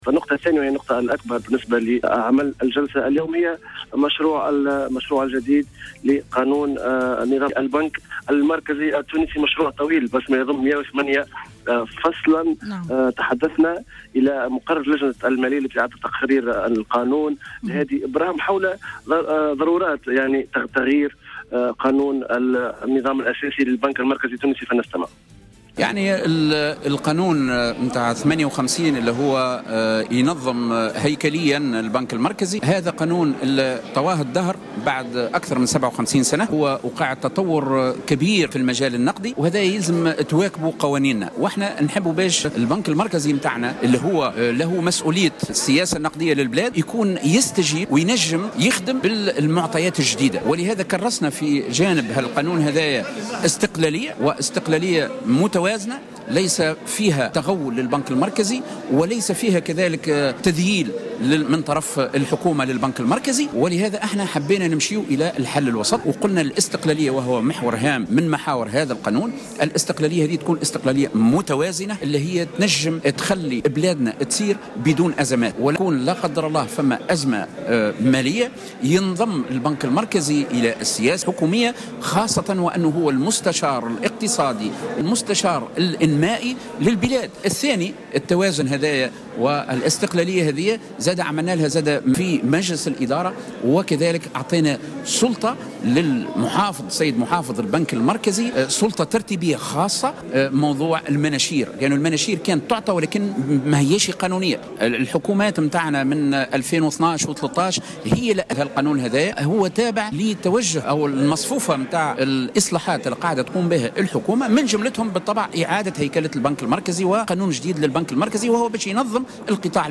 في تصريح لمراسل الجوهرة أف أم